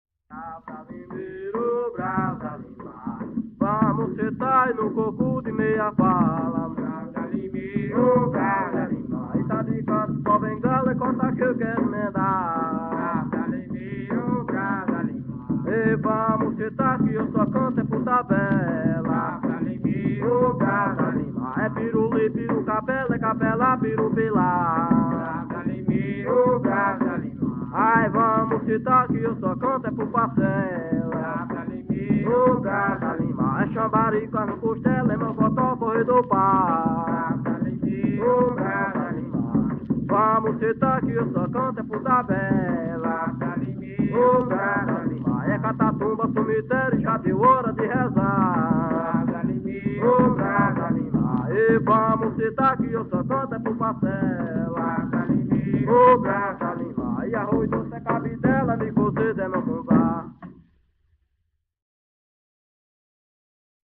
Coco- ""Bravo da limeira""